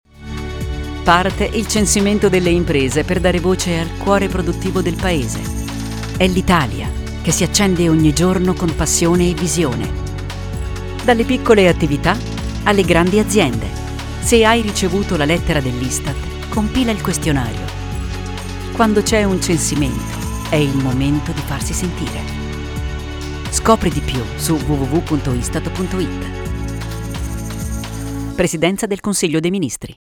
Lo spot radio
istat_censimento-imprese_radio_30.mp3